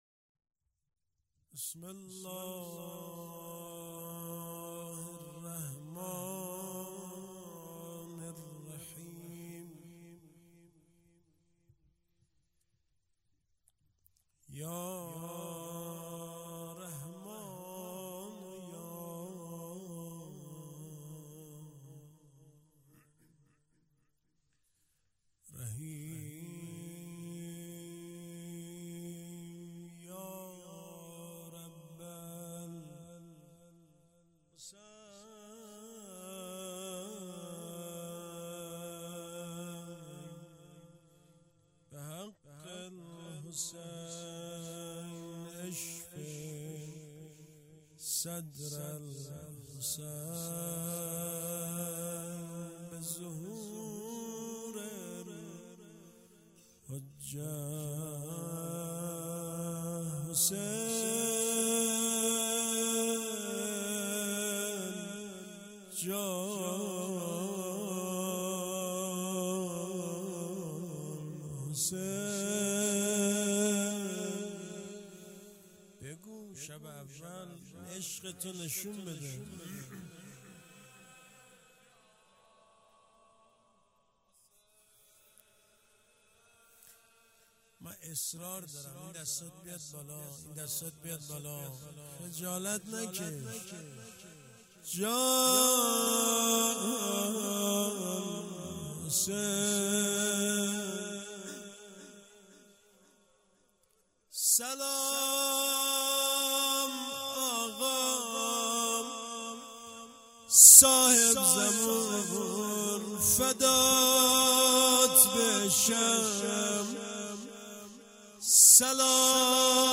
شب اول محرم - روضه- سلام آقا صاحب زمون فدات بشم